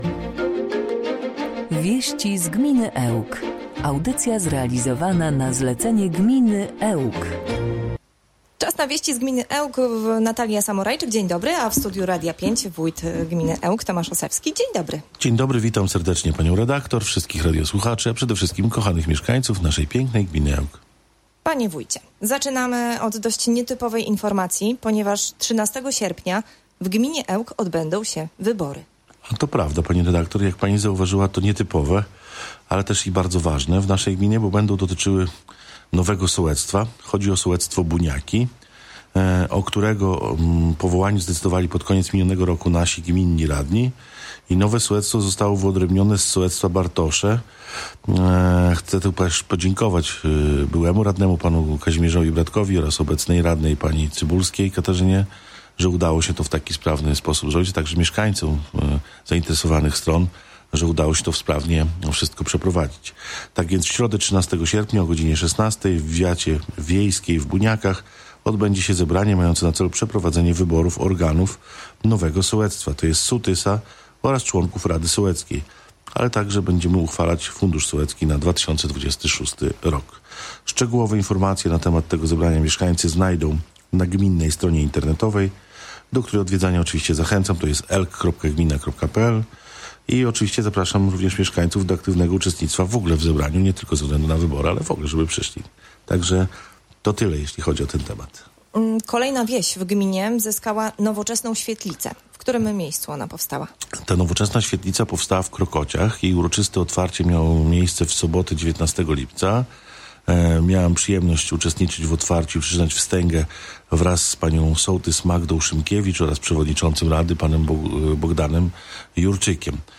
Mówił dziś (25.07) w Radiu 5 Tomasz Osewski, wójt gminy Ełk.